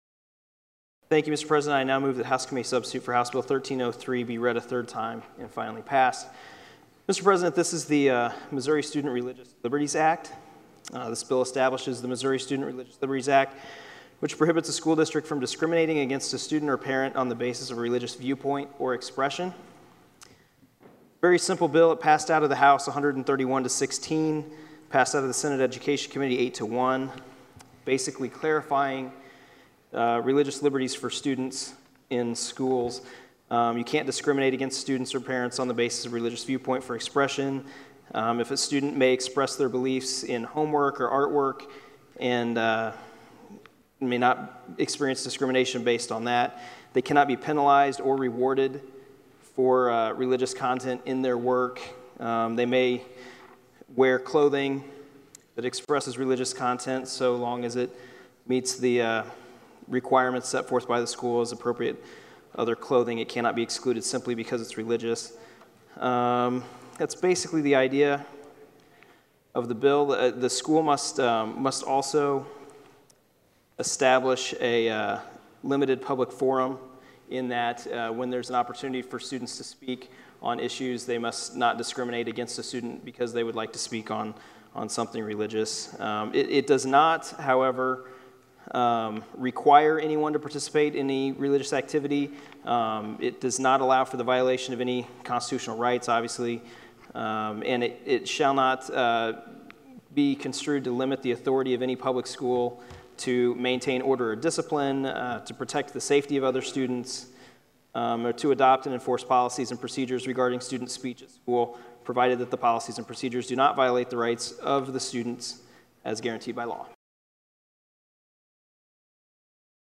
The audio and video below comes from the floor of the Missouri Senate on May 7, 2014, and feature Sen. Silvey presenting HB 1303.